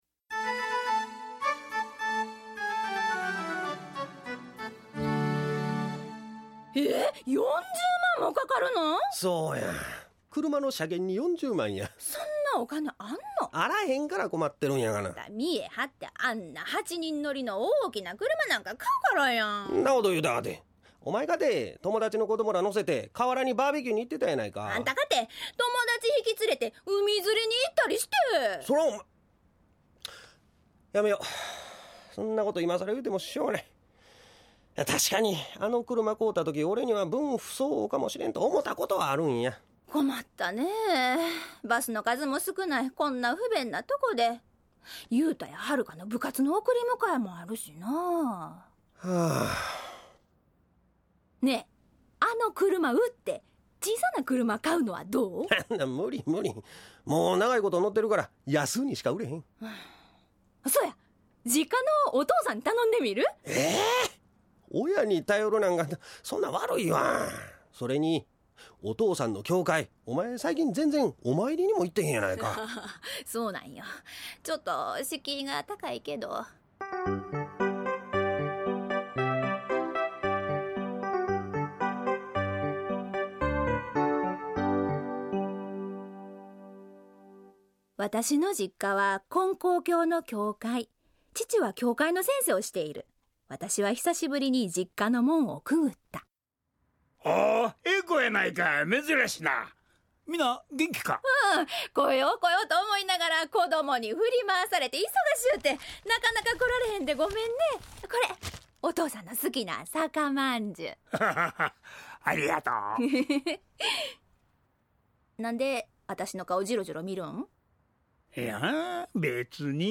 ●ラジオドラマ「ようお参りです」